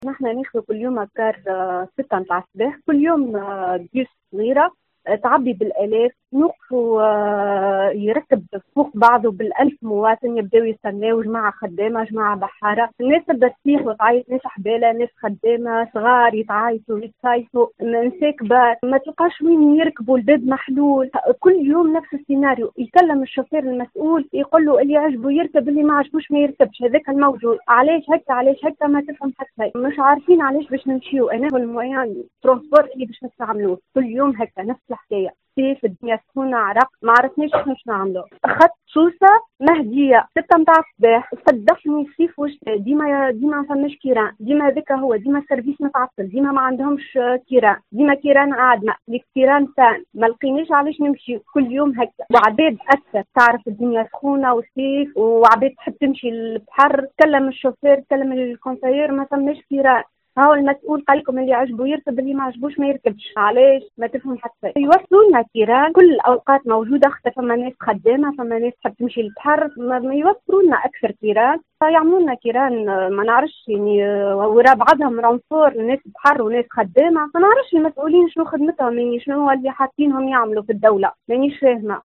حافلة سوسة المهدية: الركاب يشتكون الاكتظاظ والسائق يشتكي السب والشتم المتكرر (تسجيلات)